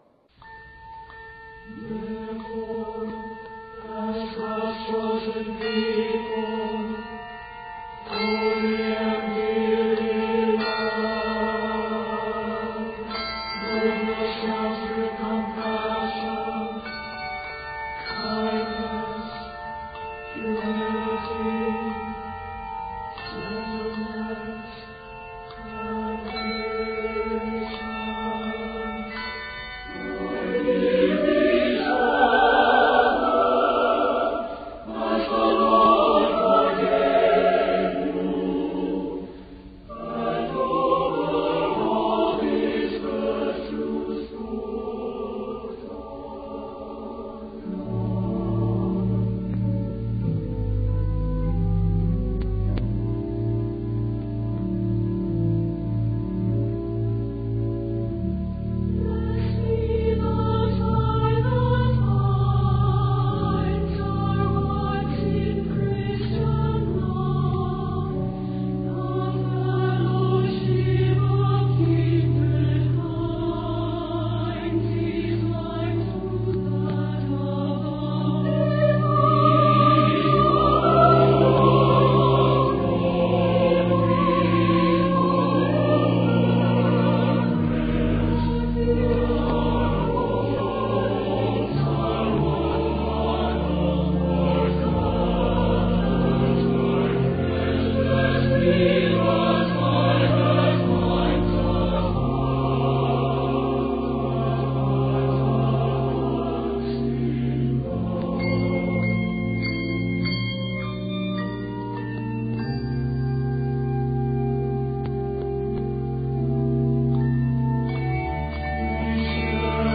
SATB anthem